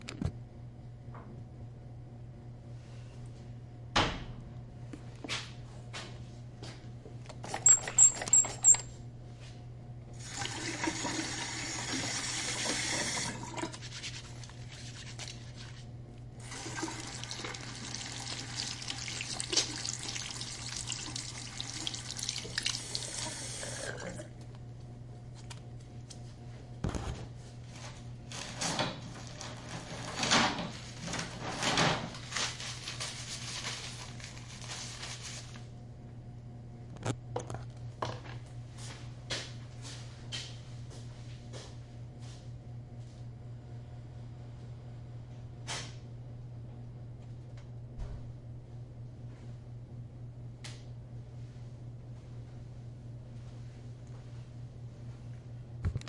有人在洗手
描述：有人在大浴室洗手，靠近水槽记录。 立体声录音 放大Mp3录像机
Tag: 清洗 肥皂 毛巾 饮水机 洗涤 浴室